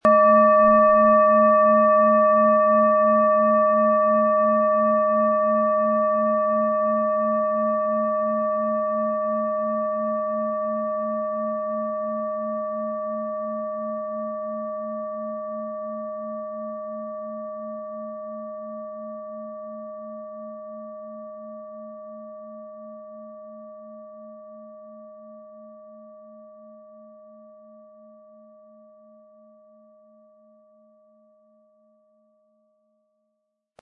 • Tiefster Ton: Wasserstoffgamma
Wie klingt diese tibetische Klangschale mit dem Planetenton Venus?
PlanetentöneVenus & Wasserstoffgamma
MaterialBronze